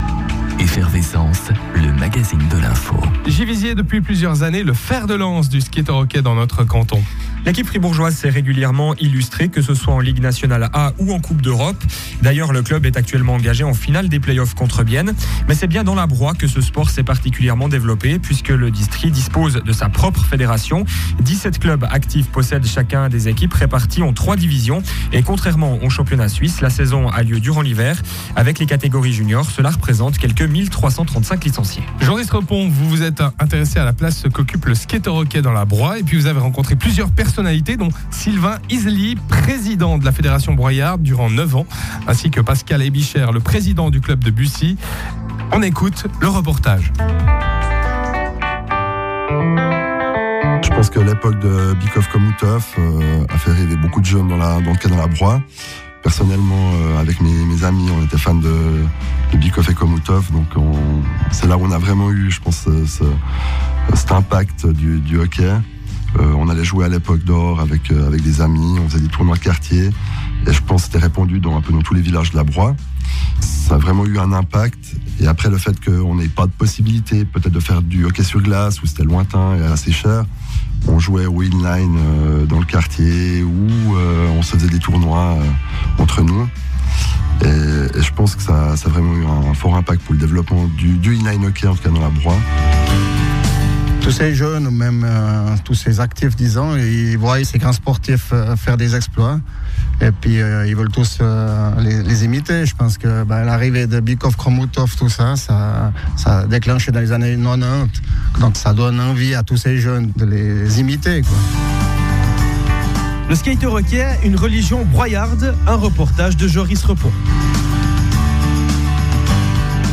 Émission Radio Fribourg du 03.11.2016 sur le Skater-Hockey